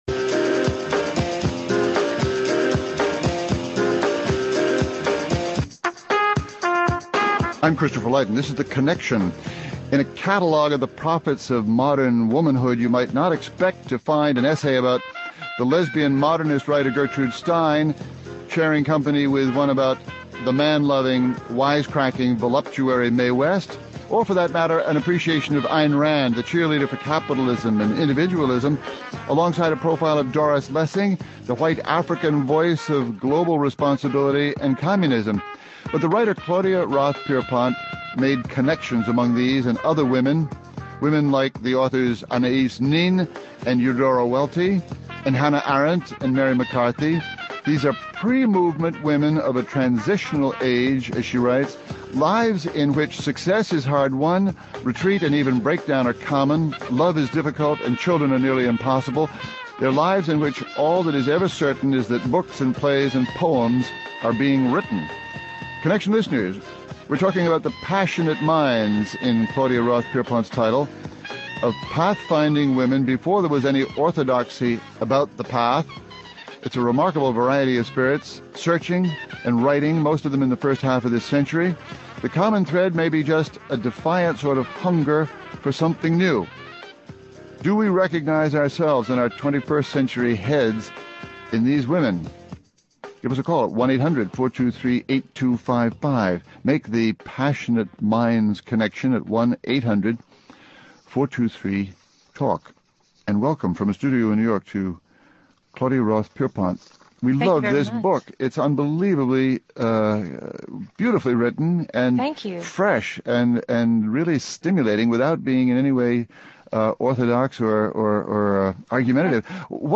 Claudia Roth Pierpont profiles twelve of them as “Passionate Minds” who rewrote the world – in this hour. (Hosted by Christopher Lydon) Guests: Claudia Roth Pierpont